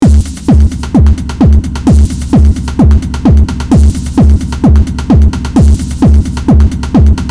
描述：我用Psycle制作的简单房屋节拍
标签： 130 bpm Deep House Loops Drum Loops 1.24 MB wav Key : Unknown
声道立体声